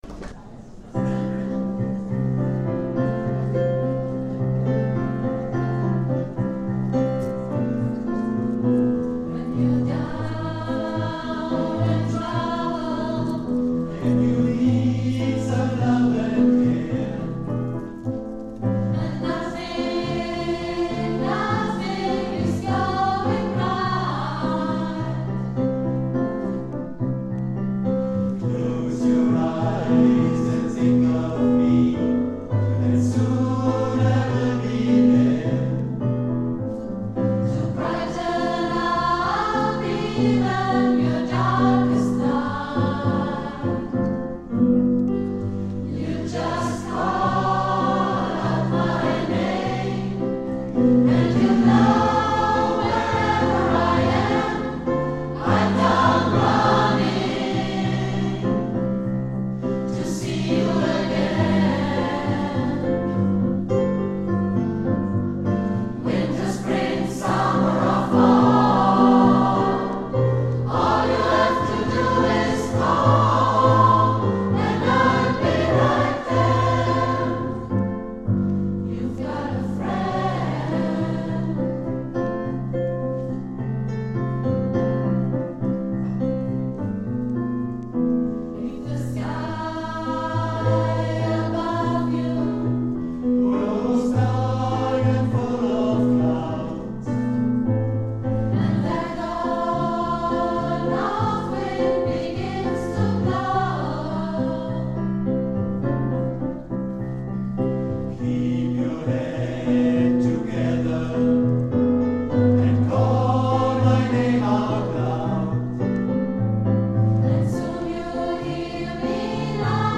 09 - Konzertaufnahmen - ChorArt zwanzigelf - Page 4
Kathy Kelly mit ChorArt zwanzigelf 17.03.2019 – You’ve Got a Friend